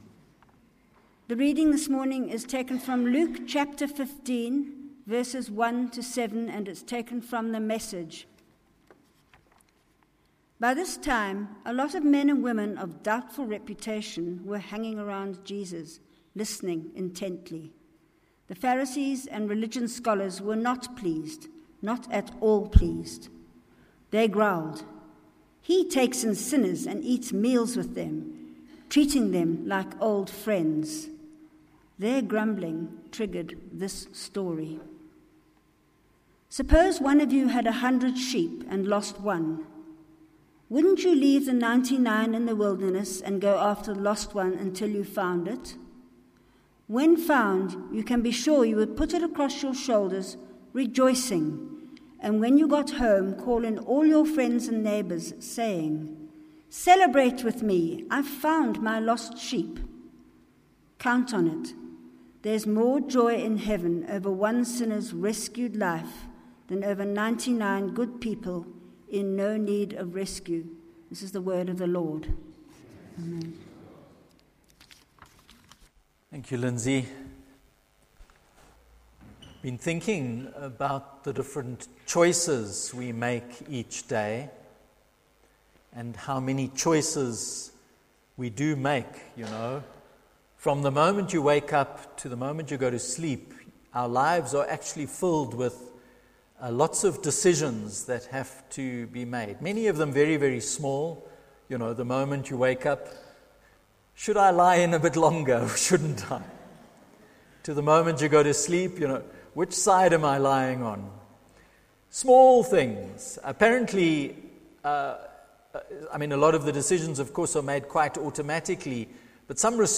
Sermons
9:30am Fusion Service from Trinity Methodist Church, Linden, Johannesburg